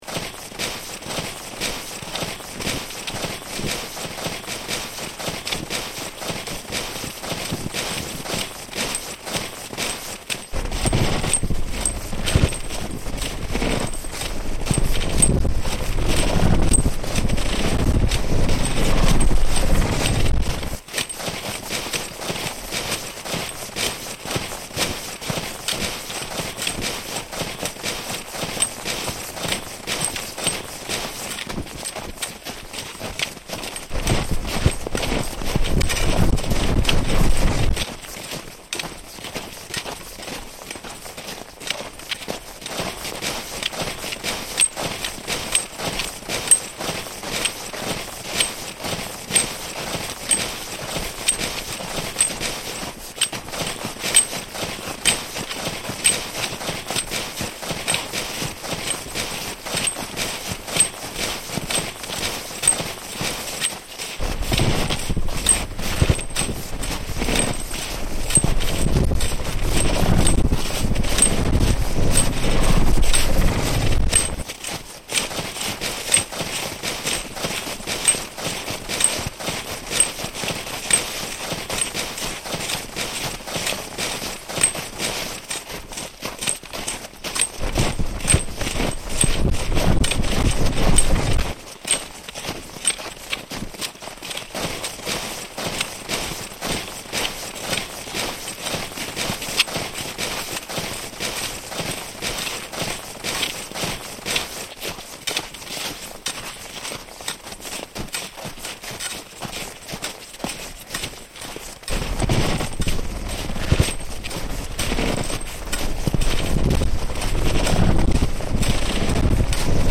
Snow, ski poles, distorted perceptions